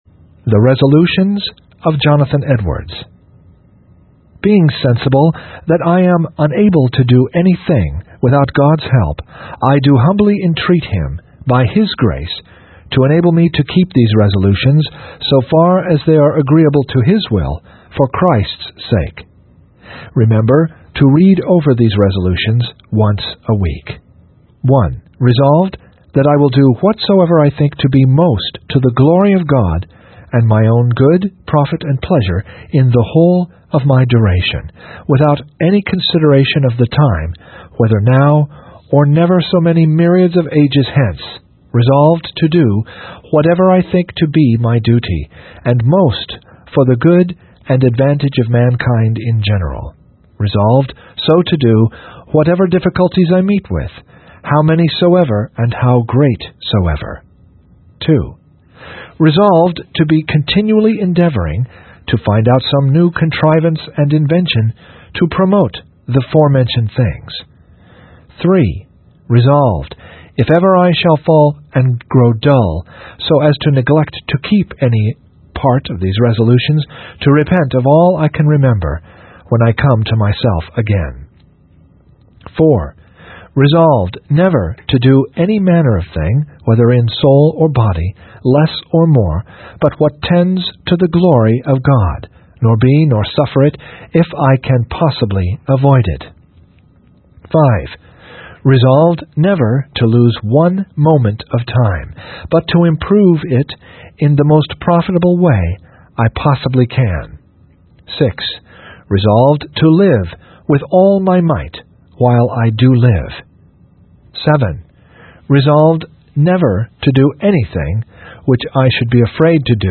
Resolutions (Reading) by Jonathan Edwards | SermonIndex